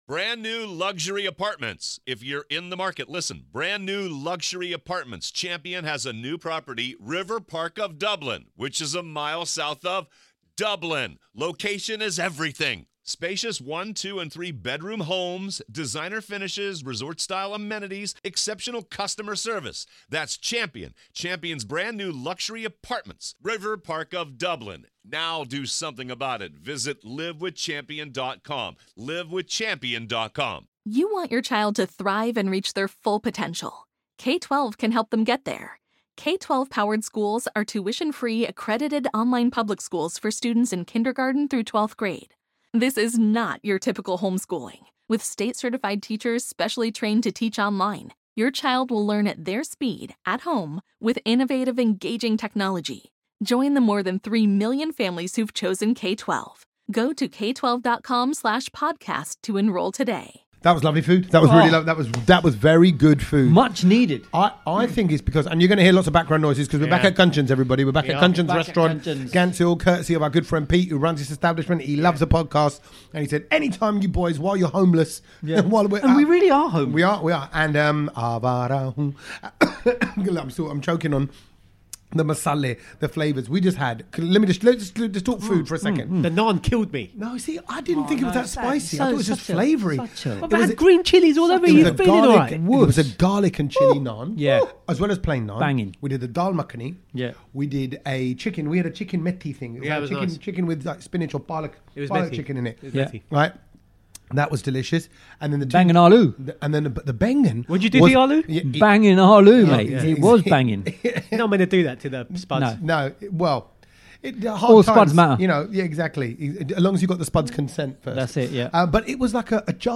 We’re at Kanchans restaurant for this podcast talking about even more travel antics and my birthday weekend!